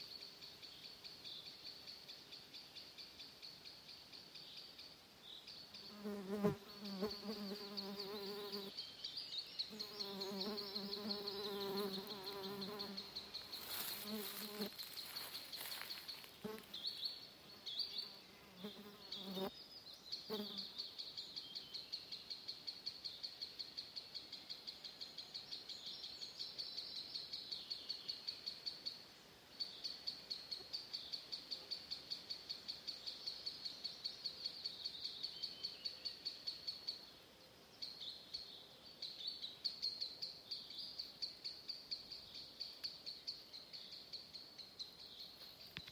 Birds -> Waders ->
Green Sandpiper, Tringa ochropus
StatusAgitated behaviour or anxiety calls from adults